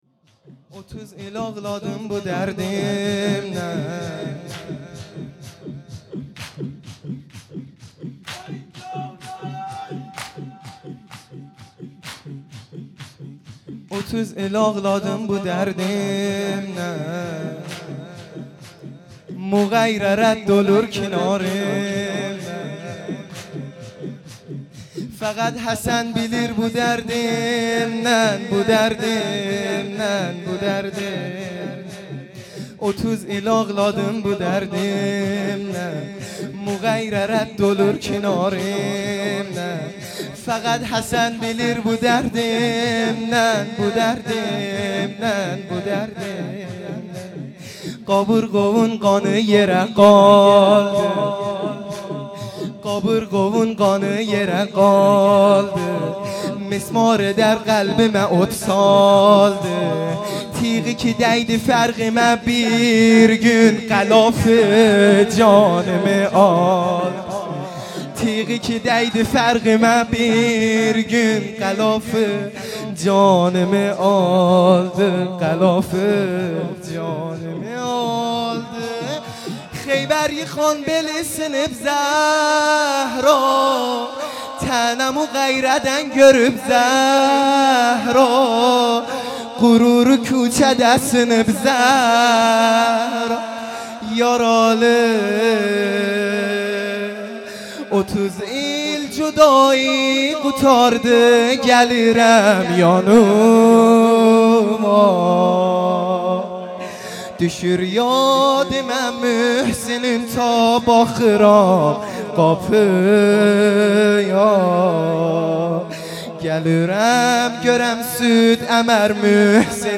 شور ترکی | اوتوز ایل آغلادیم